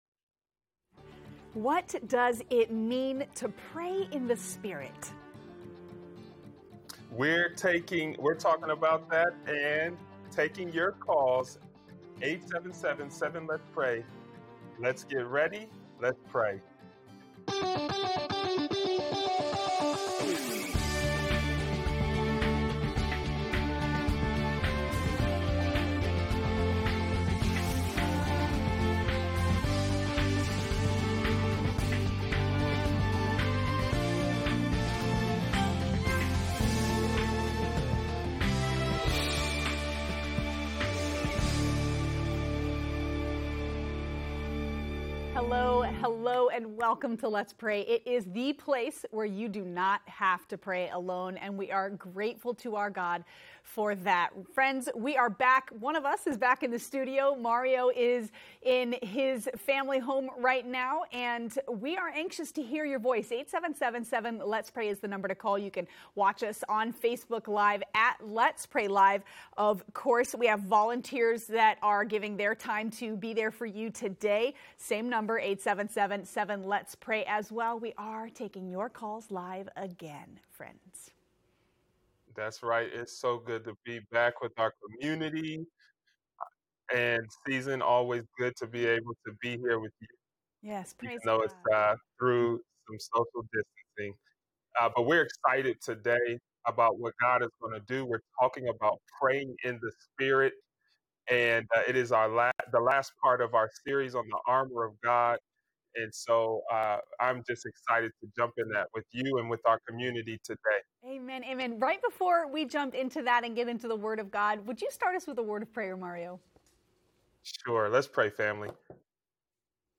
What does it mean to pray at all times? Today we will talk about Praying in the Spirit and we’re taking your calls live.